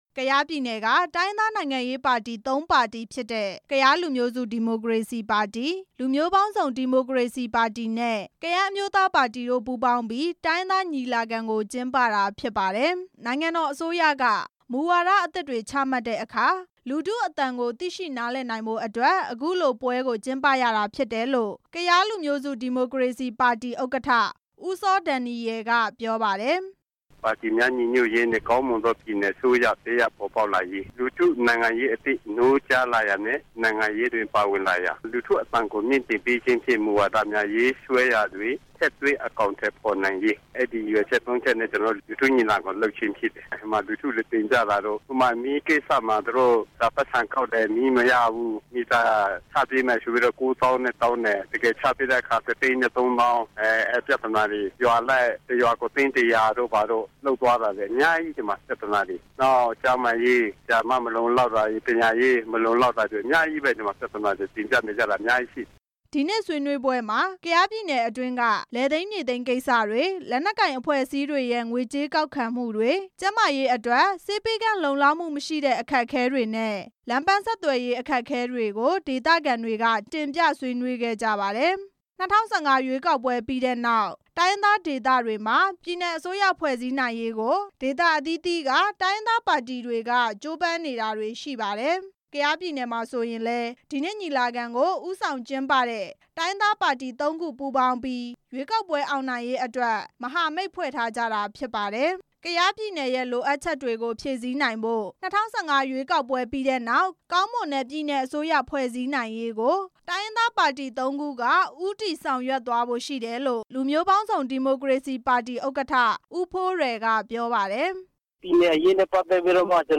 ကယားပြည်နယ်က ပါတီ ၃ ခု ညီလာခံကျင်းပနေတဲ့အကြောင်း တင်ပြချက်